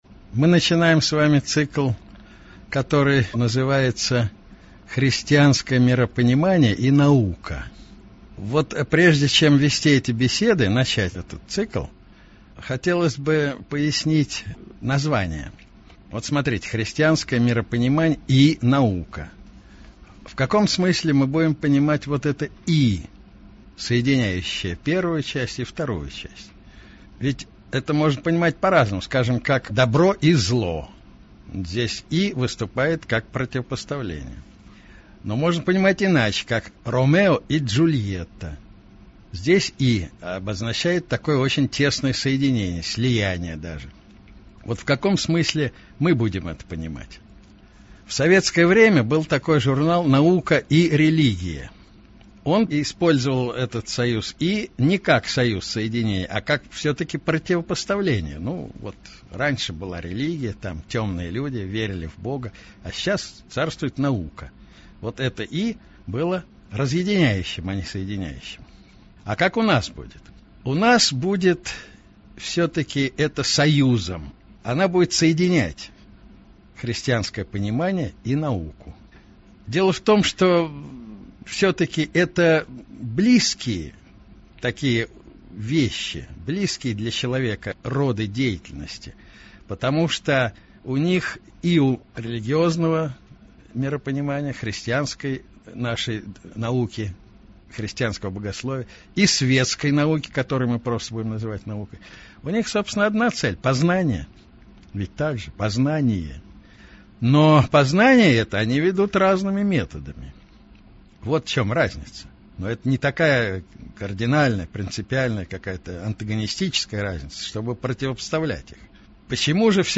Аудиокнига Христианское миропонимание и наука | Библиотека аудиокниг